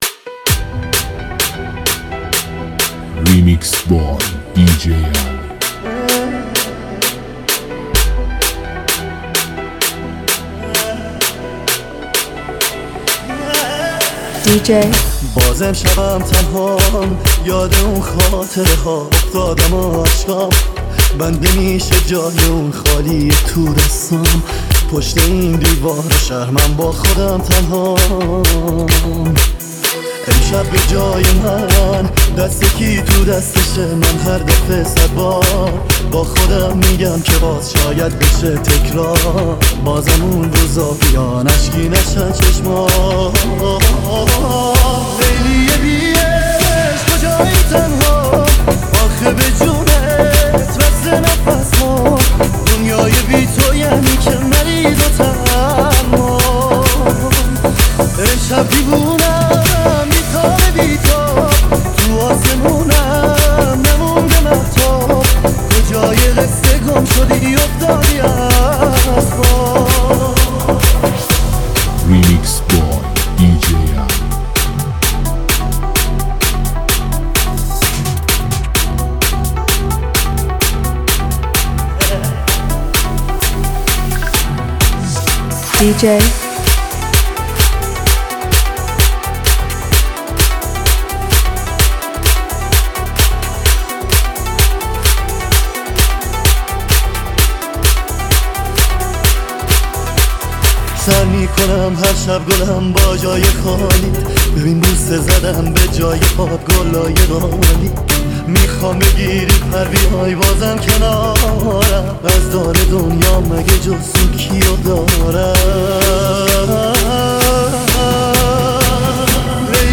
لذت بردن از موسیقی پرانرژی و بیس دار، هم‌اکنون در سایت ما.